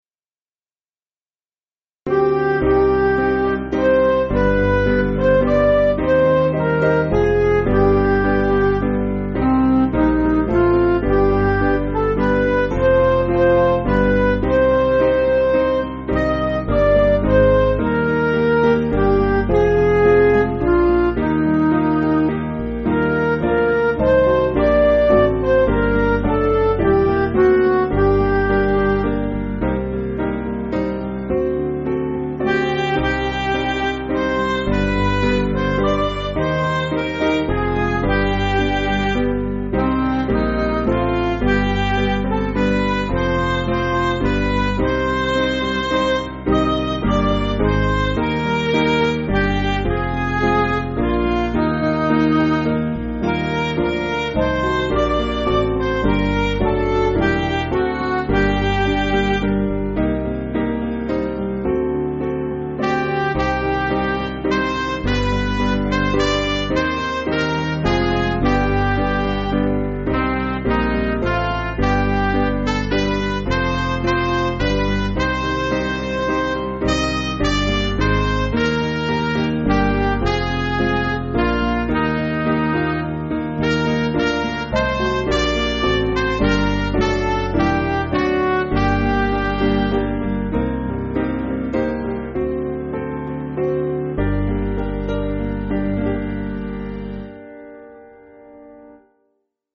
Piano & Instrumental
(CM)   3/Gm